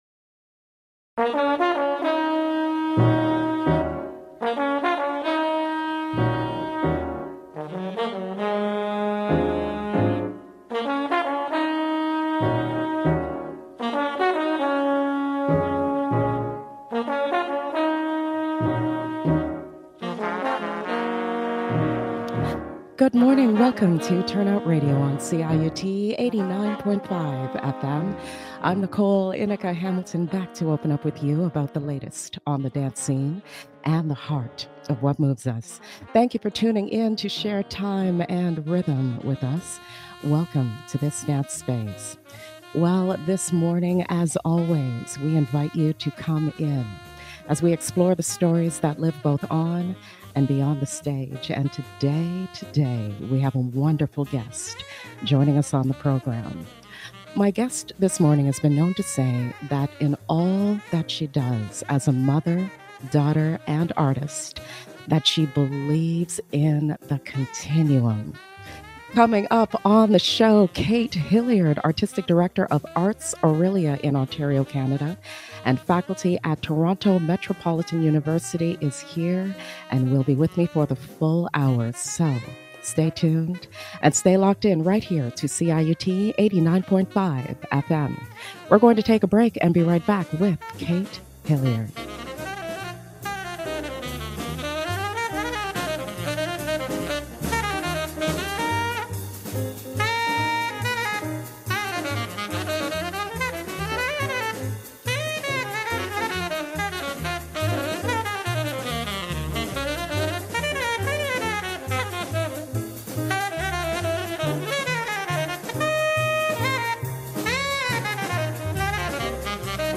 ive on CIUT 89.5 FM